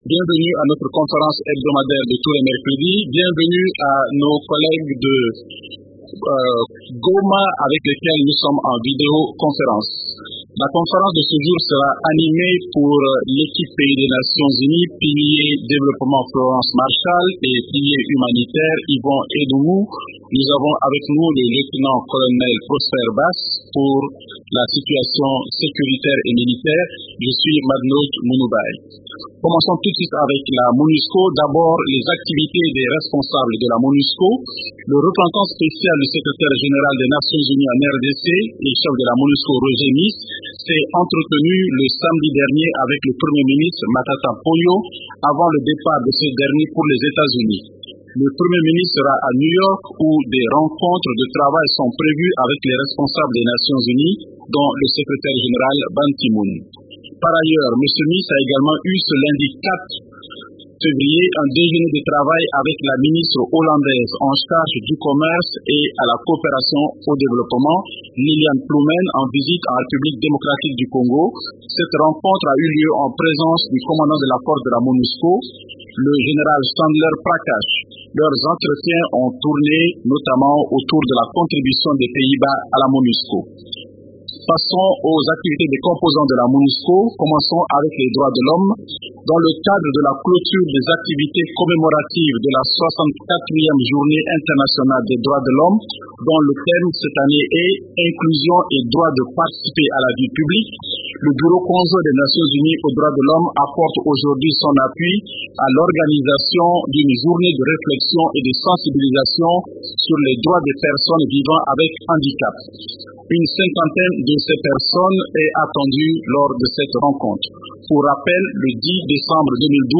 Conférence du 6 février 2013
La conférence hebdomadaire des Nations unies du mercredi 6 février a porté sur les sujets suivants: